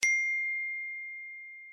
tchatNotification.ogg